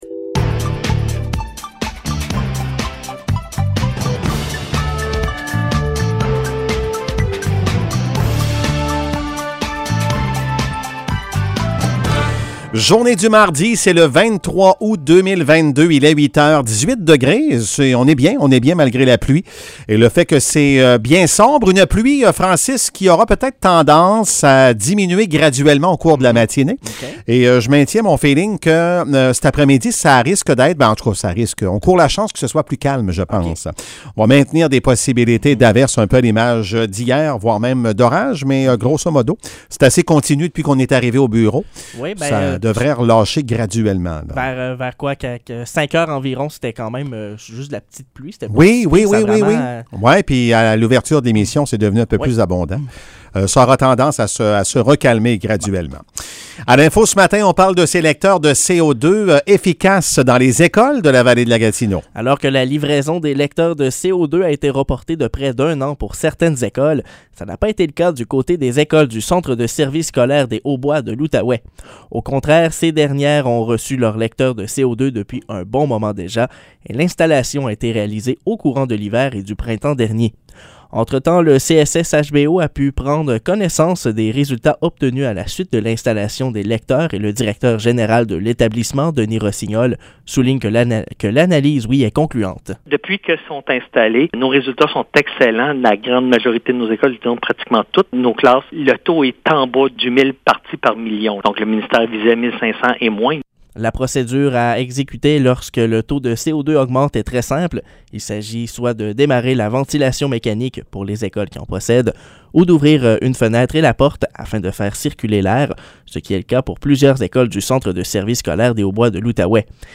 Nouvelles locales - 23 août 2022 - 8 h